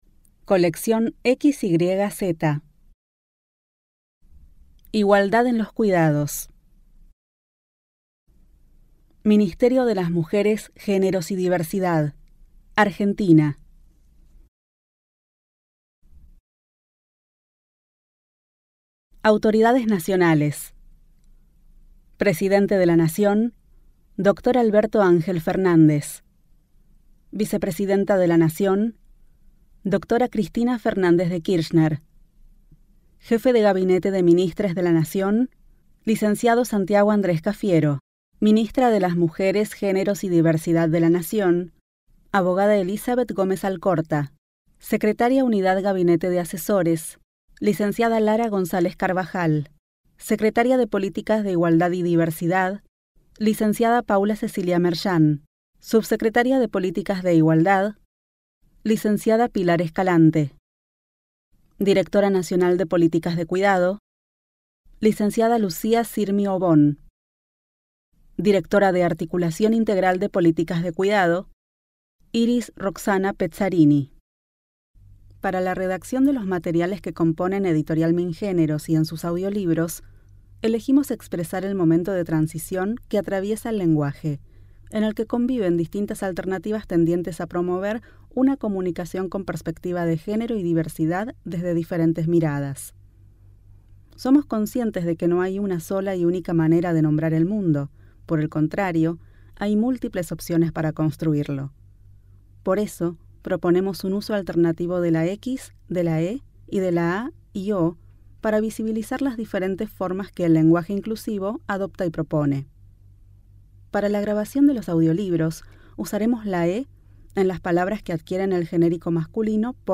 Igualdad en los cuidados - Audiolibro Completo6eb7.mp3